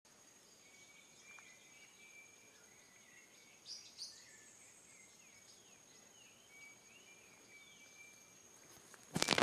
Pavonine Cuckoo (Dromococcyx pavoninus)
Life Stage: Adult
Location or protected area: Reserva Privada y Ecolodge Surucuá
Condition: Wild
Certainty: Observed, Recorded vocal